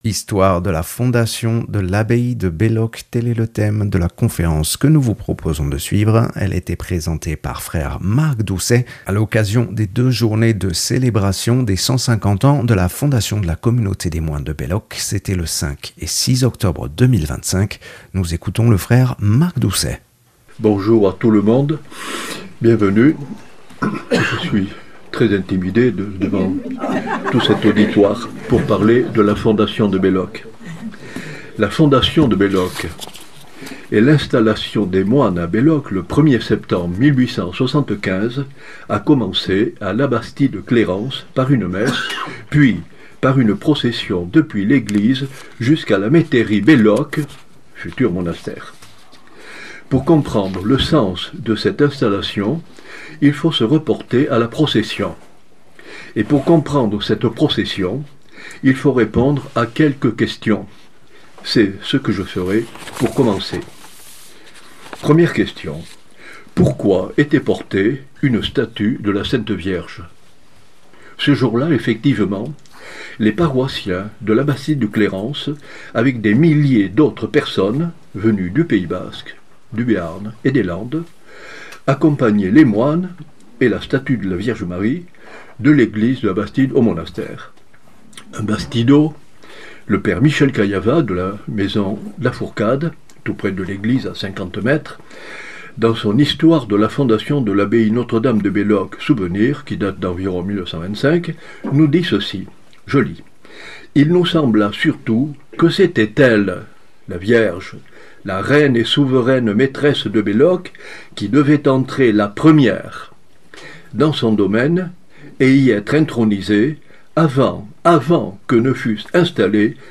Une conférence
enregistré le 6 octobre à l’occasion des célébrations des 150 ans de la fondation de la communauté des moines de Belloc.